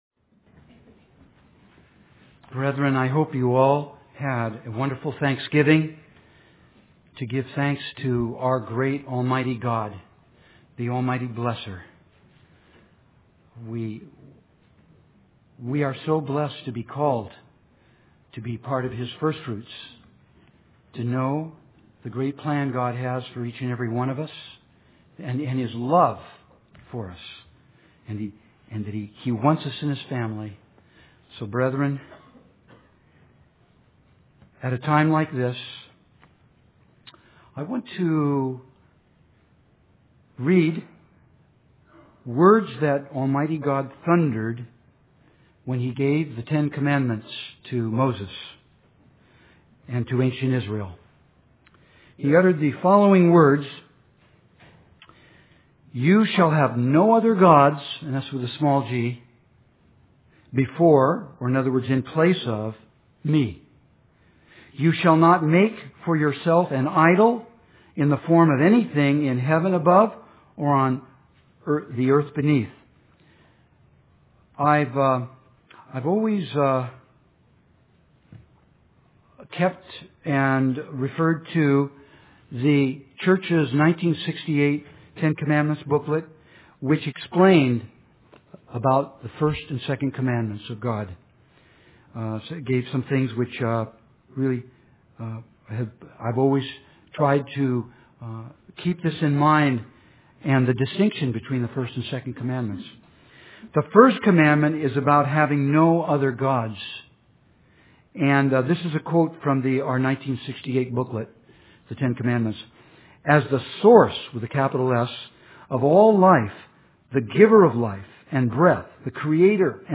Have No Human Gods or Idols | United Church of God